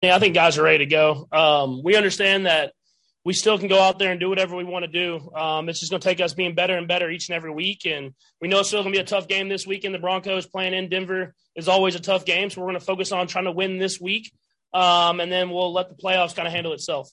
Quarterback Patrick Mahomes says they have been focused this week.
1-6-patrick-mahomes.mp3